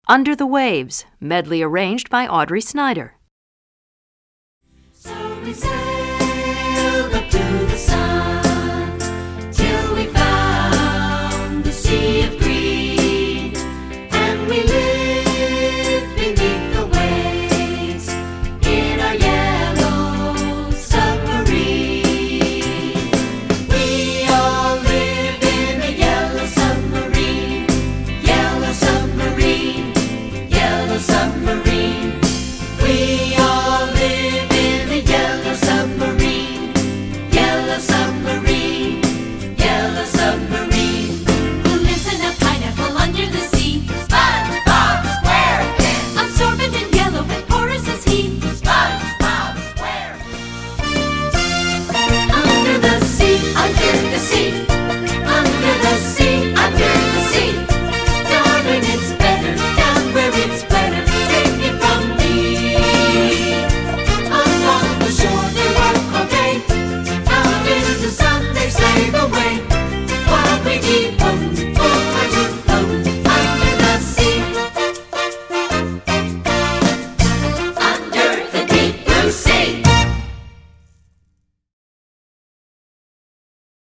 Voicing: 3-Part Mixed